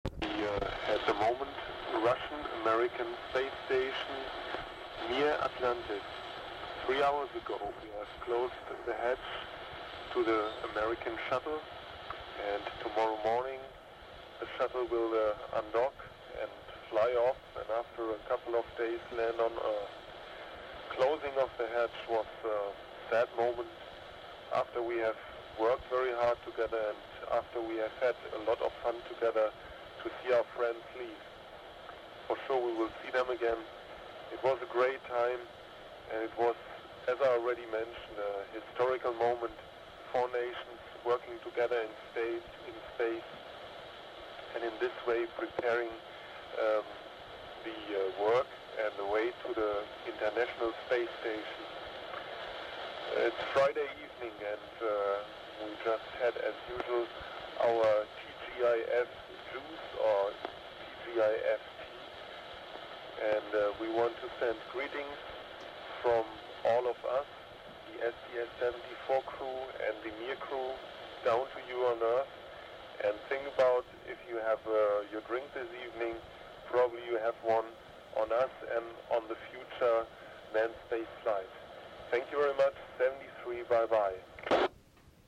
Audio Below was captured as the Mir Space Station Orbited over Maui Hawaii, with HAM operators onboard.